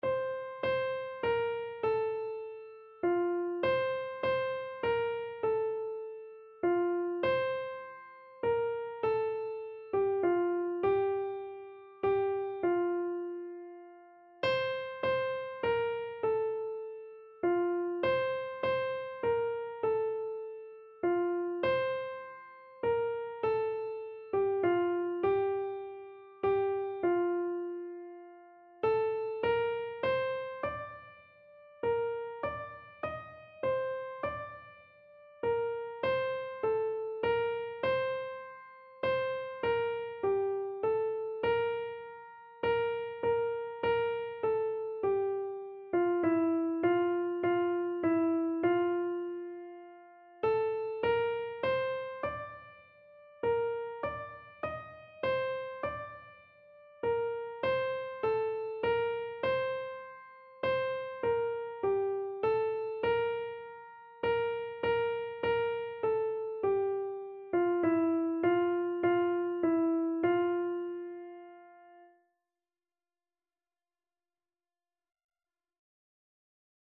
Free Sheet music for Keyboard (Melody and Chords)
F major (Sounding Pitch) (View more F major Music for Keyboard )
3/4 (View more 3/4 Music)
Instrument:
Classical (View more Classical Keyboard Music)